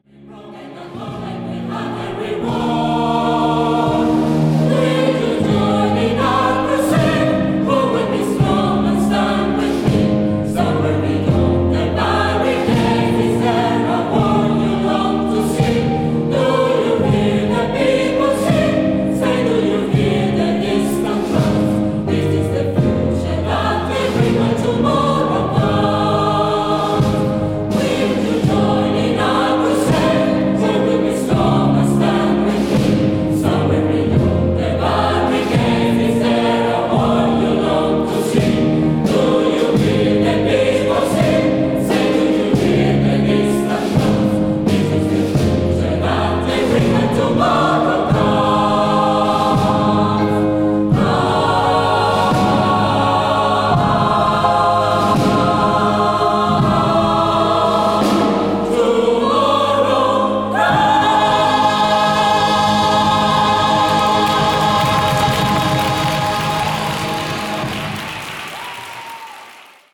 Audios en directo (concierto de estreno,  2 de Diciembre de 2017):
Dependiendo del estilo y del tema concreto, cantamos a capella o con instrumentación de acompañamiento opcional (teclado).